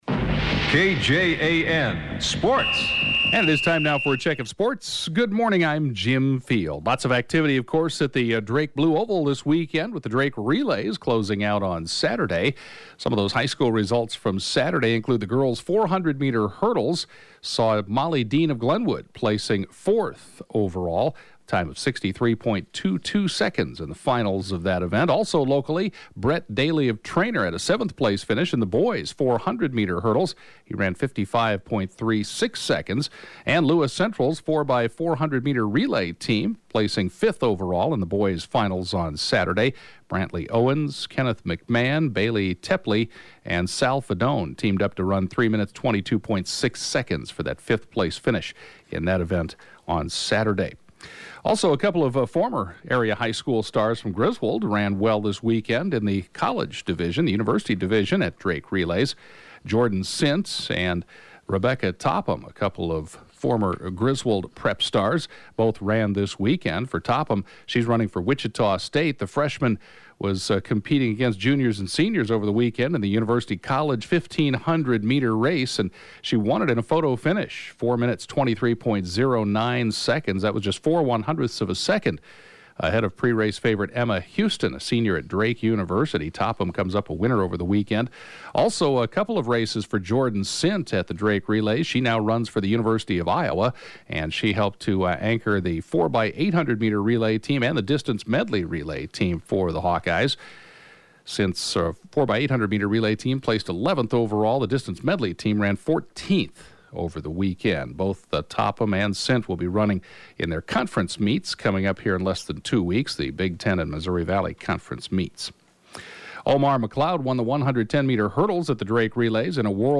(Podcast) KJAN Morning Sports report, 5/28/2016